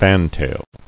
[fan·tail]